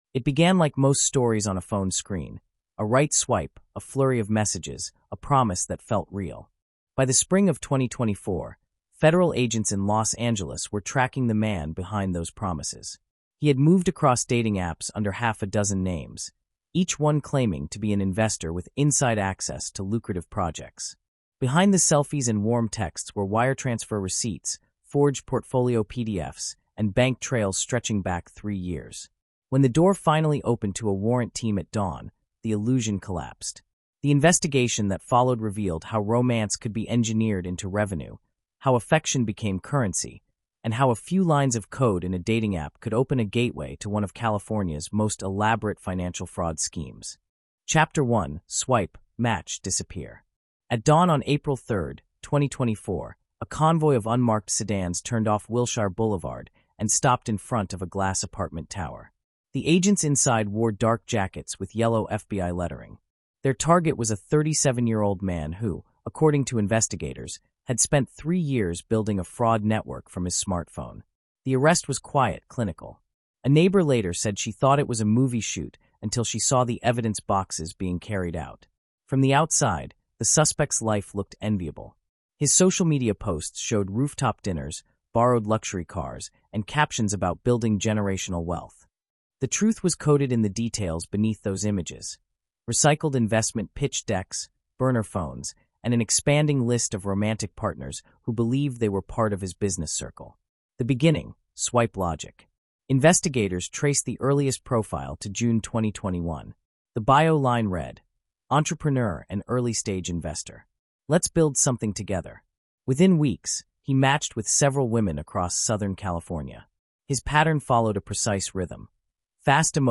The Digital Paper Trail is a tense, forensic-style true crime narrative following a California man who transformed dating apps into his personal financial machine. Between 2021 and 2024, he built a network of fraudulent relationships through Tinder, Hinge, and Bumble — presenting himself as a wealthy investor looking for partners in both life and business.